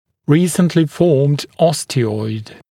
[‘riːsntlɪ fɔːmd ˈɔstɪɔɪd][‘ри:снтли фо:мд ˈостиойд]недавно сформированная остеоидная ткань